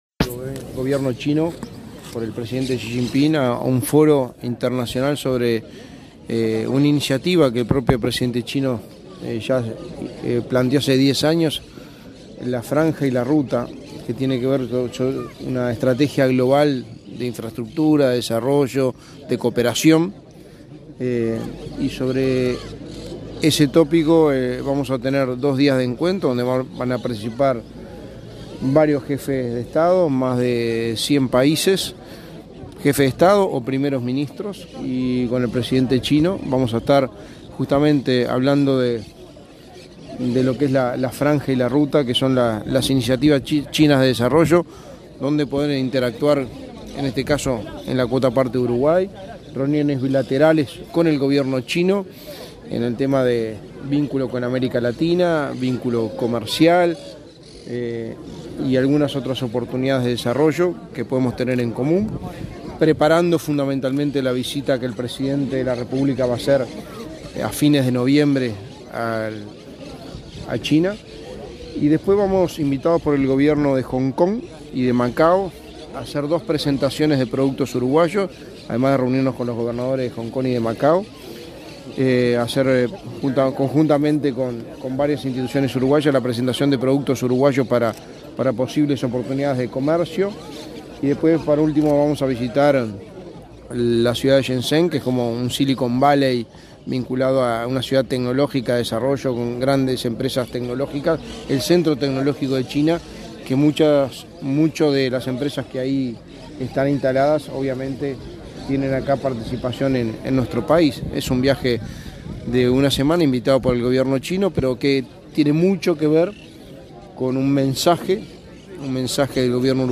Declaraciones a la prensa del secretario de Presidencia, Álvaro Delgado
Declaraciones a la prensa del secretario de Presidencia, Álvaro Delgado 12/10/2023 Compartir Facebook X Copiar enlace WhatsApp LinkedIn El secretario de la Presidencia, Álvaro Delgado, dialogó con la prensa en Florida, luego de participar en el acto conmemorativo del 198.° aniversario de la Batalla de Sarandí.